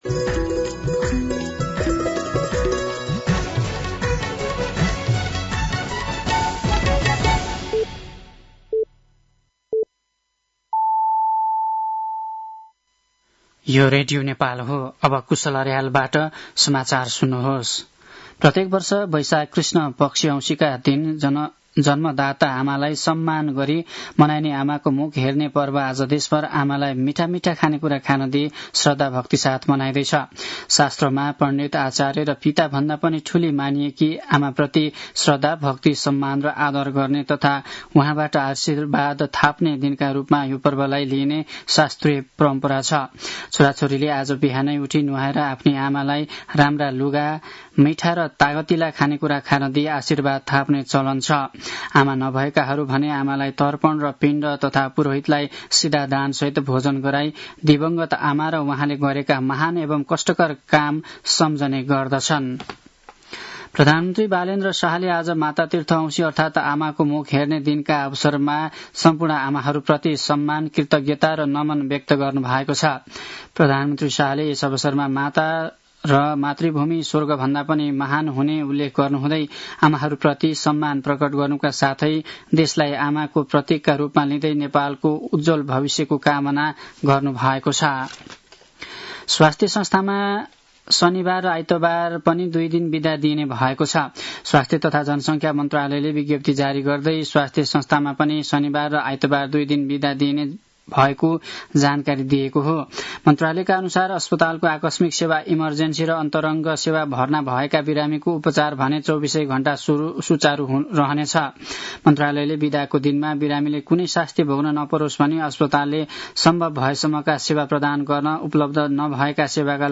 साँझ ५ बजेको नेपाली समाचार : ४ वैशाख , २०८३